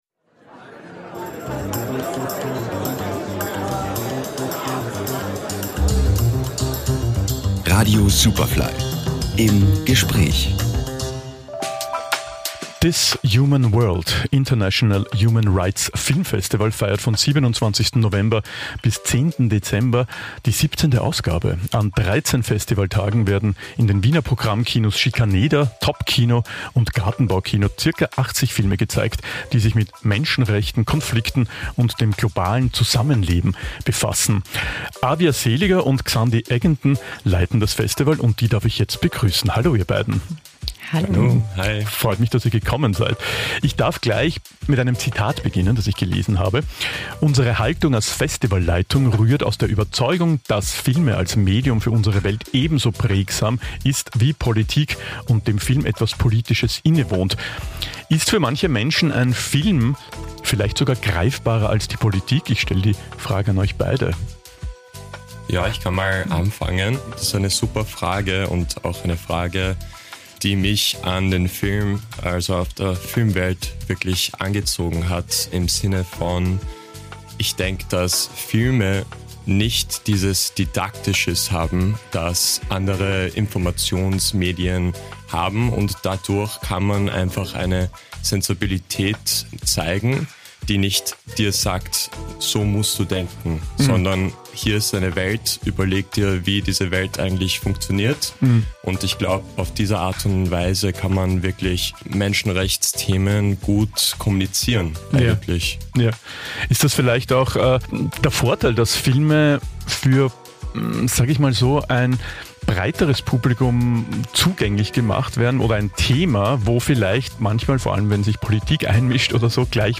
Superfly Interviews | This Human World Festival